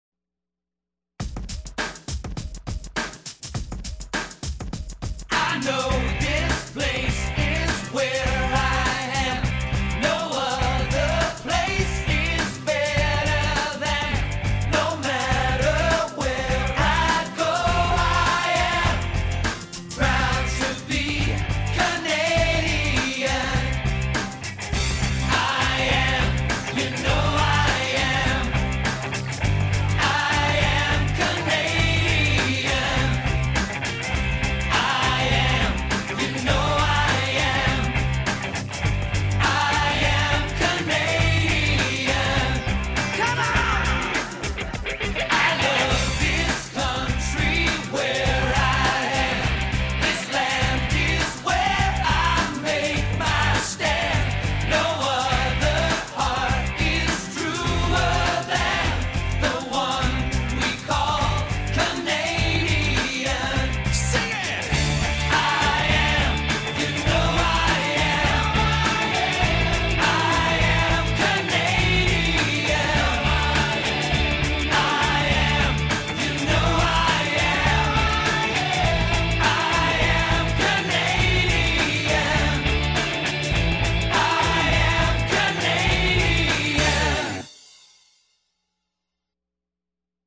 Jó, fülbemászó muzsika…